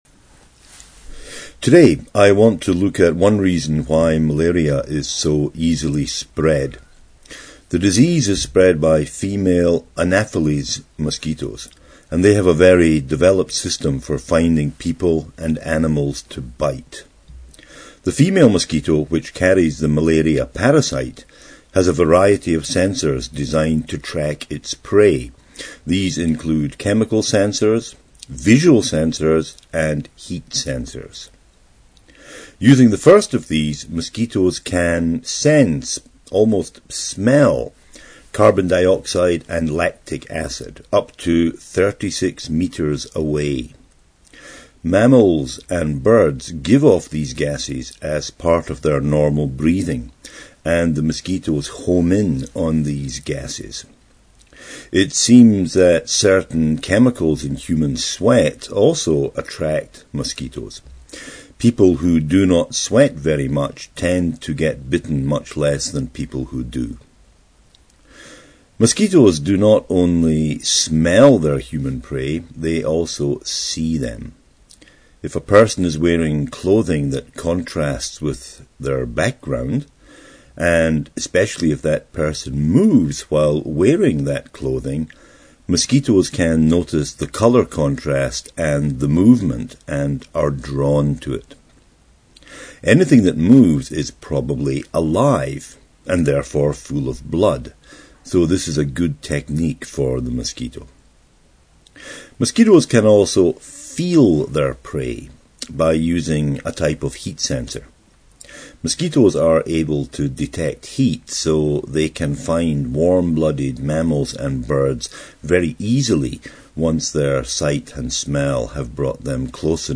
Listening passage